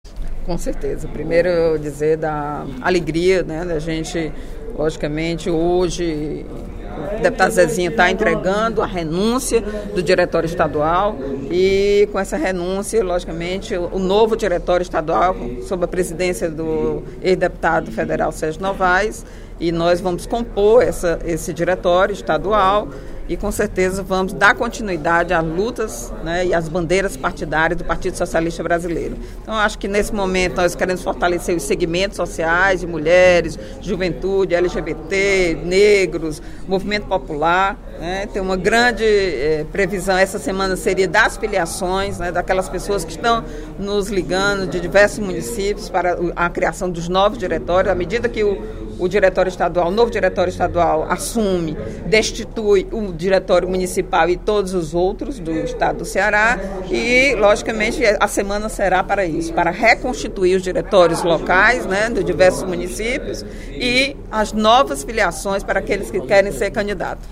Em pronunciamento durante o primeiro expediente da sessão plenária desta sexta-feira (27/09), a deputada Eliane Novais (PSB) informou que a direção nacional do PSB está trabalhando para construir uma comissão provisória para a legenda no Ceará, que terá a missão de reconstruir as instâncias municipais do partido, tanto em Fortaleza quanto no interior do Estado.